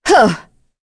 Naila-Vox_Attack1.wav